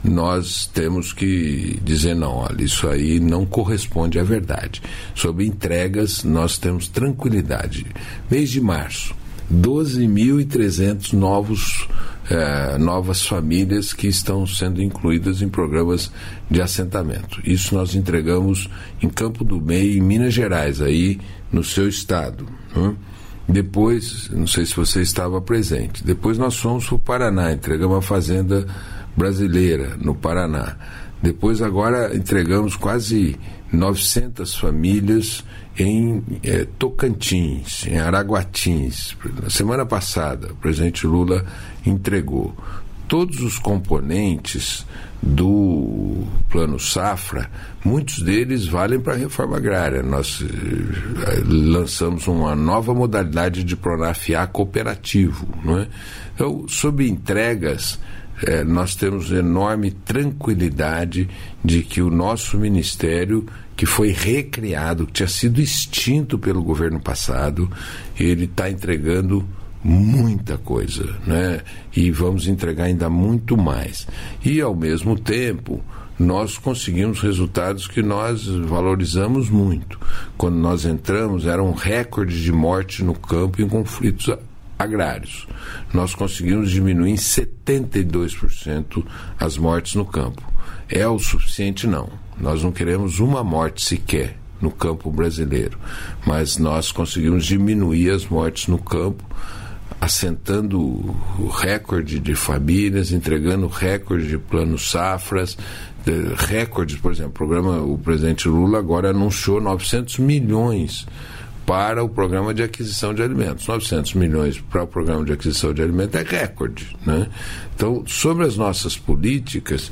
Íntegra da entrevista do ministro do Desenvolvimento Agrário e Agricultura Familiar, Paulo Teixeira, no programa "Bom Dia, Ministro", desta terça-feira (1), nos estúdios da EBC em Brasília (DF).